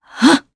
Isolet-Vox_Attack2_jp.wav